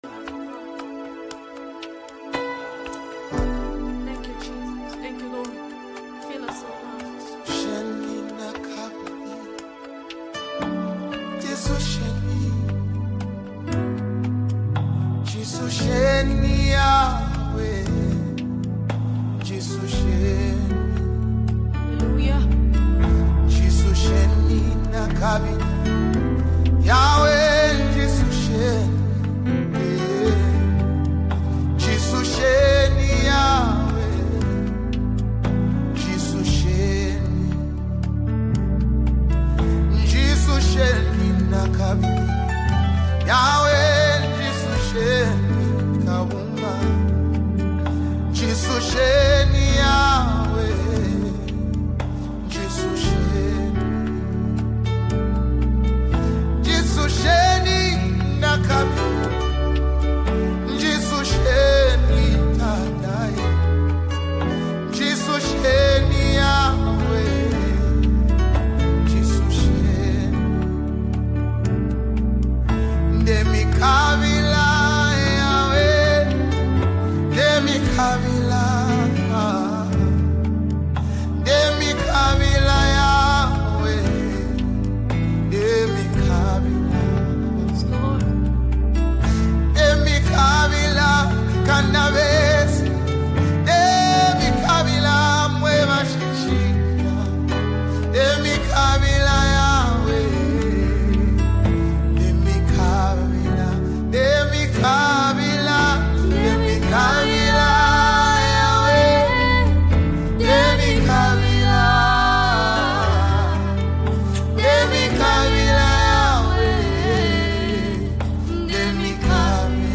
soothing vocals
soulful instrumental arrangement